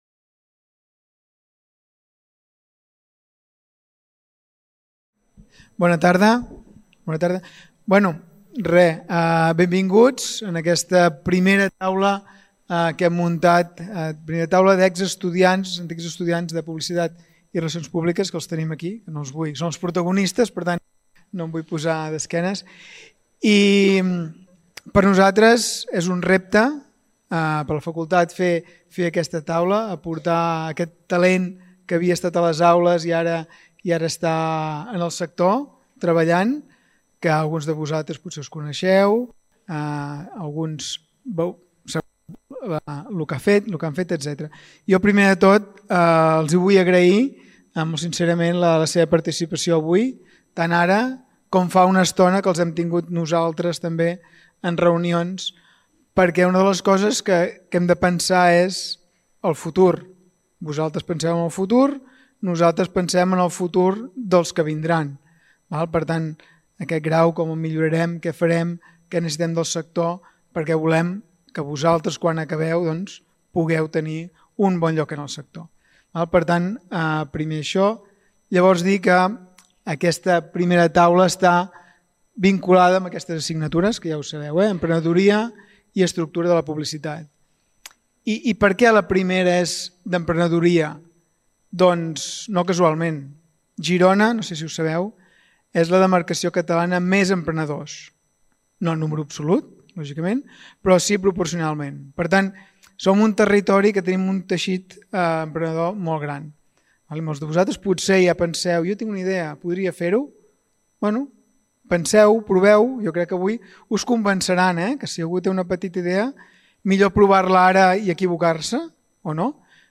Primera Taula Rodona d'Antics Estudiants del Grau en Publicitat i Relacions Públiques de la Universitat de Girona.
La trobada s'ha centrat en el tema de l'emprenedoria i ha servit per conèixer el testimoni dels ponents i compartir reflexions sobre els principals aprenentatges d’esdevenir una persona emprenedora, les motivacions per fundar cada projecte i fer una mirada al passat i reflexionar sobre l’evolució de cada ponent. A continuació, cada ponent ha exposat la seva visió sobre com es pot motivar l’estudiantat a emprendre el seu projecte. Finalment, l’acte ha conclòs amb un torn obert de paraules  Aquest document està subjecte a una llicència Creative Commons: Reconeixement – No comercial – Compartir igual (by-nc-sa) Mostra el registre complet de l'element